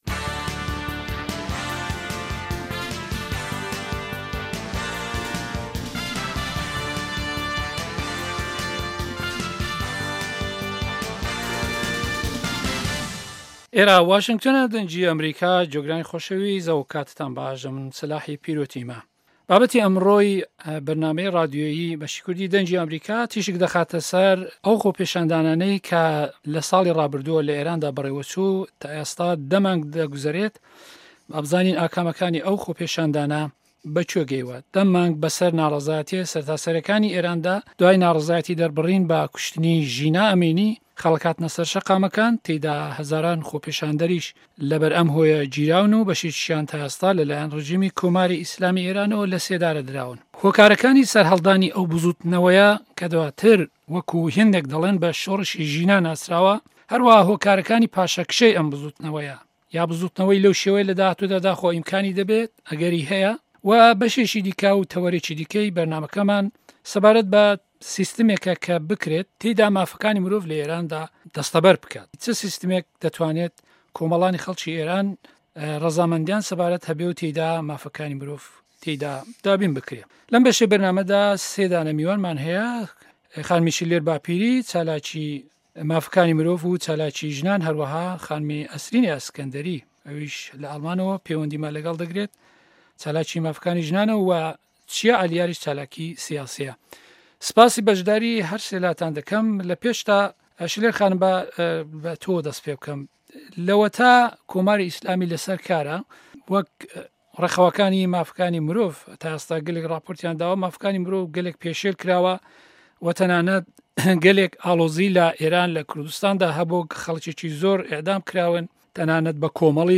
مێزگرد